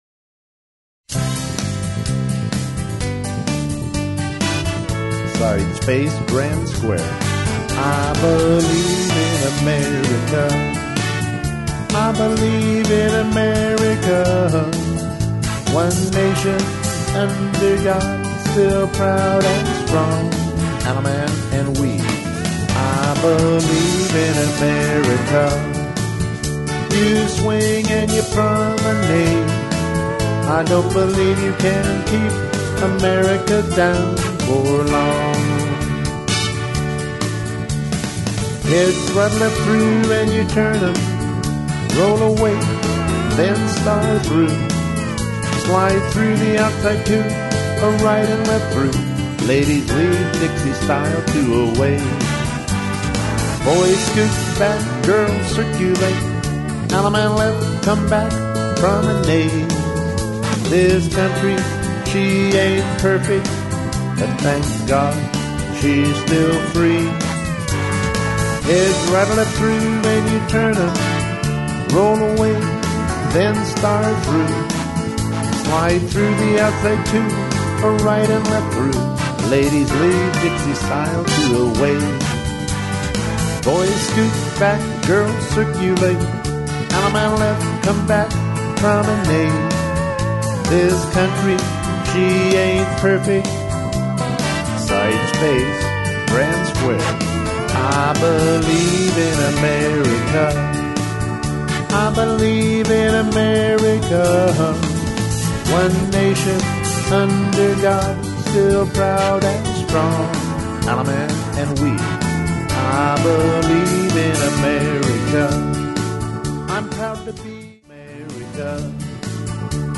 Vocal Tracks